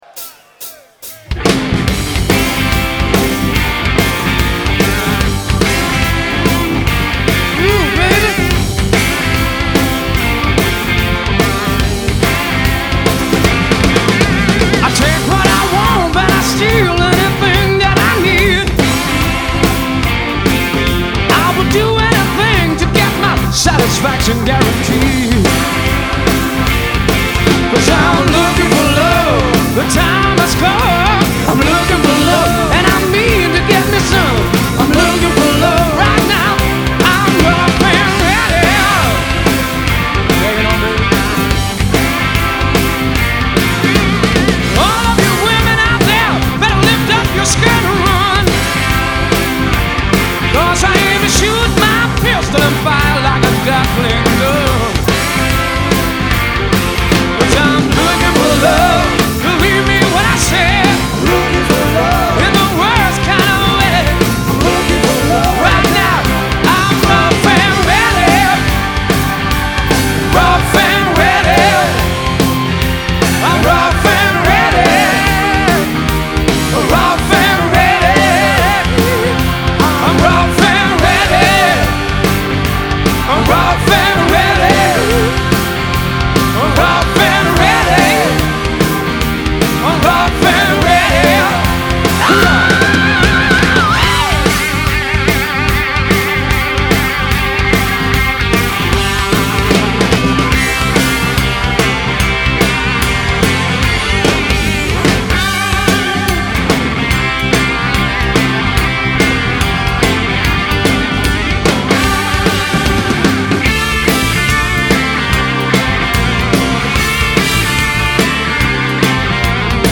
en directo